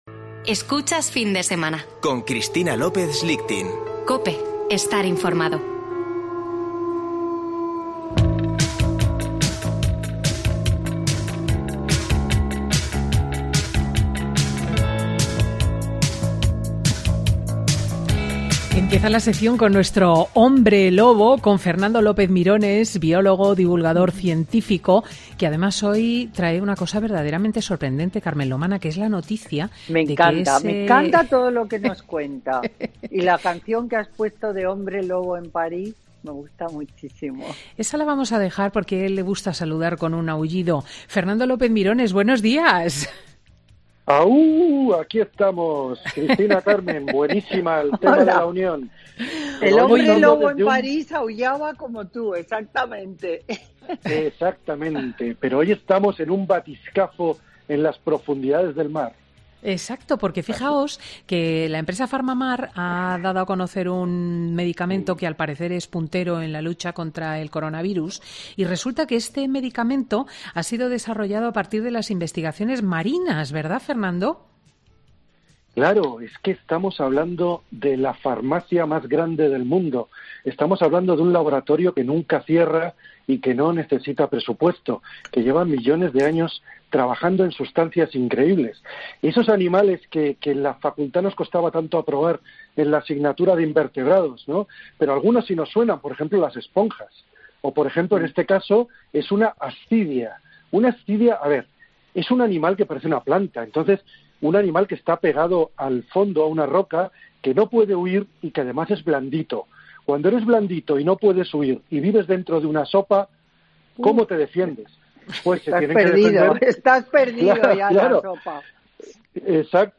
se oye más entrecortado porque la lección de ciencia es bajo el agua.